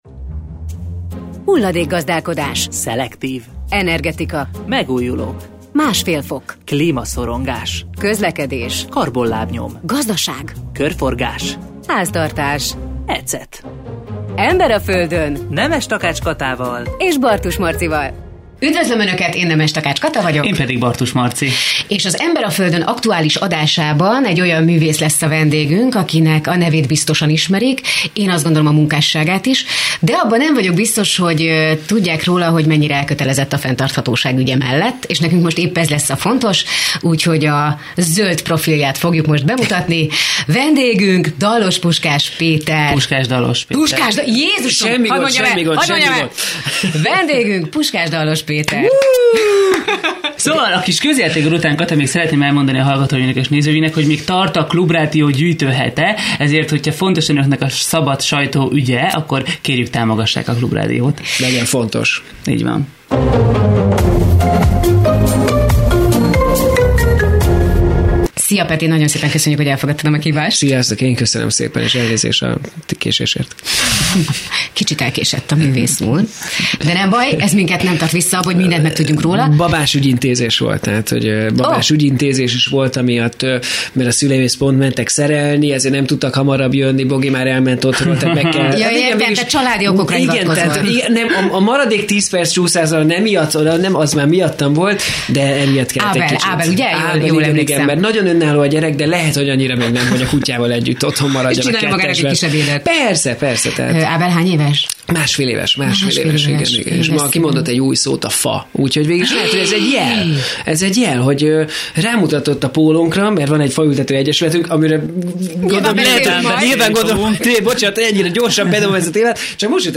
Hol kerül be a képbe az automatizáció és az AI? 48:15 Play Pause 22h ago 48:15 Play Pause Lejátszás később Lejátszás később Listák Tetszik Kedvelt 48:15 Ebben az epizódban beszélgetőpartnerünk Vágujhelyi Ferenc, a NAV elnöke, aki elkalauzol minket a magyar adóhatóság digitális átalakulásának és adatkezelési rendszerének világába.